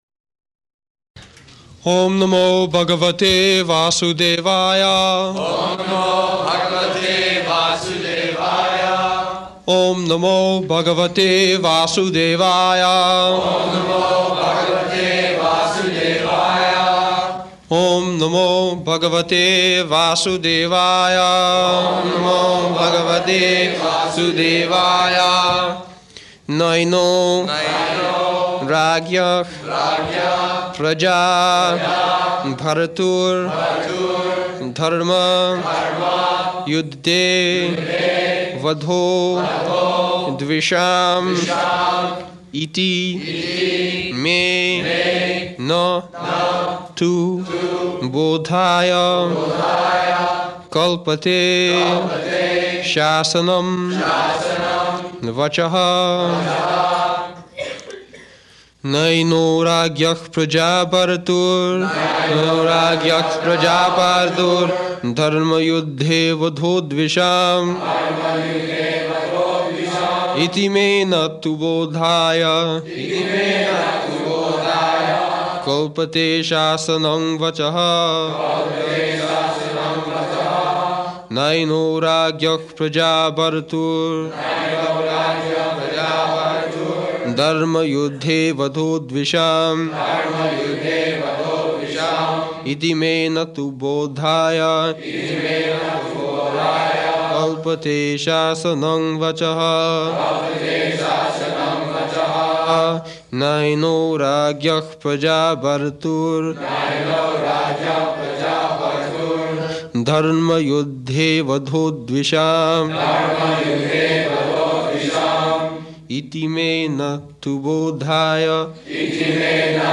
May 12th 1973 Location: Los Angeles Audio file
[leads chanting of verse] [Prabhupāda and devotees repeat] naino rājñaḥ prajā-bhartur dharma-yuddhe vadho dviṣām iti me na tu bodhāya kalpate śāsanaṁ vacaḥ [ SB 1.8.50 ] [break] Prabhupāda: Word meaning.